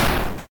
bang.mp3